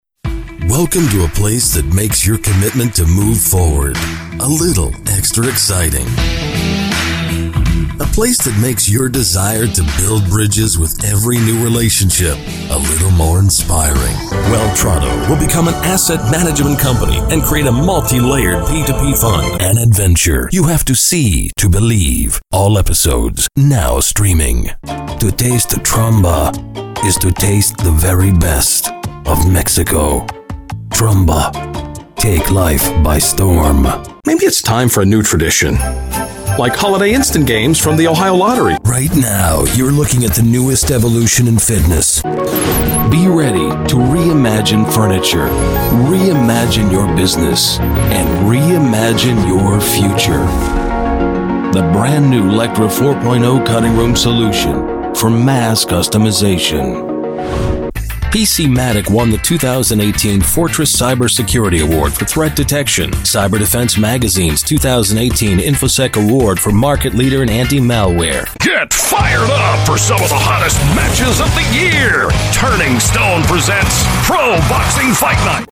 Male
Adult (30-50), Older Sound (50+)
My voice is Versatile. It can be Soothing or Rugged if needed. My voice is also Deep, Warm, Friendly and Authoritative.
My Vocal style feels like it's more Resonant, Professional, yet Approachable and Persuasive.
Main Demo
Words that describe my voice are Engaging, Comforting, persuasive.